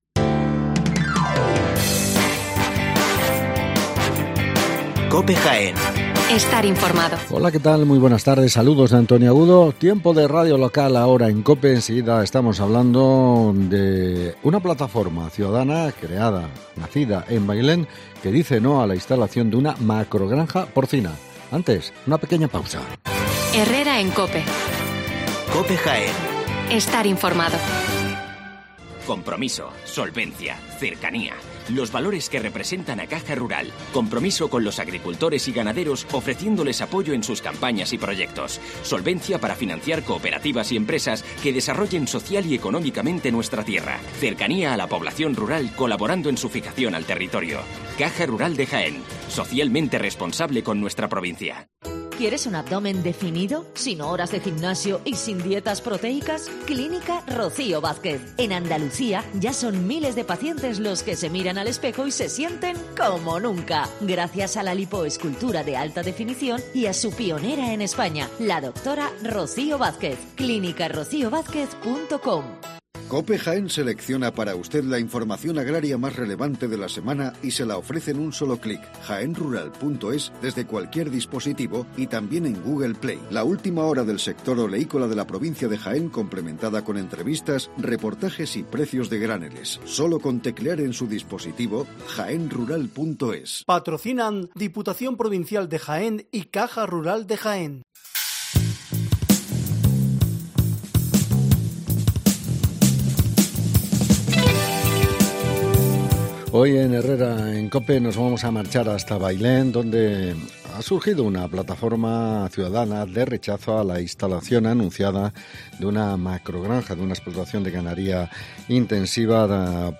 Hablamos con la plataforma que dice NO a la macrogranja porcina en Bailén